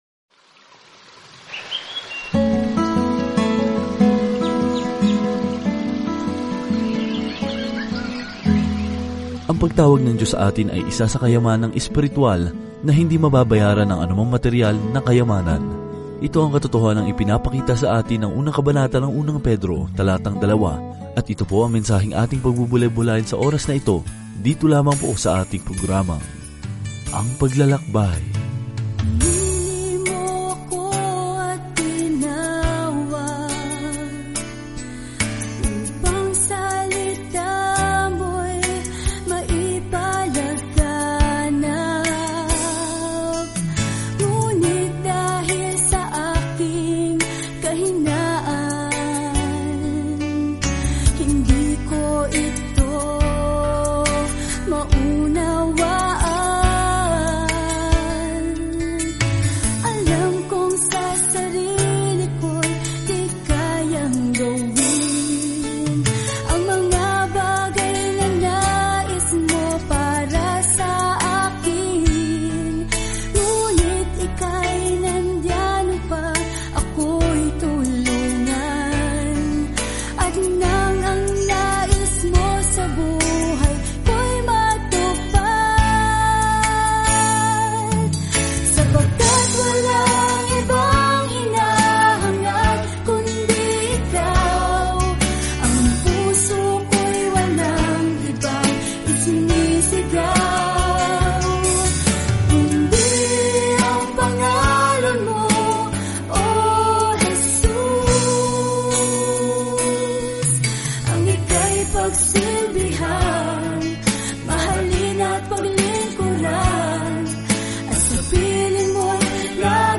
Banal na Kasulatan 1 Pedro 1:2 Araw 1 Umpisahan ang Gabay na Ito Araw 3 Tungkol sa Gabay na ito Kung nagdurusa ka para kay Jesus, ang unang liham na ito mula kay Pedro ay hinihikayat ka na sumusunod ka sa mga yapak ni Jesus, na unang nagdusa para sa atin. Araw-araw na paglalakbay sa 1 Pedro habang nakikinig ka sa audio study at nagbabasa ng mga piling talata mula sa salita ng Diyos.